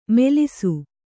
♪ melisu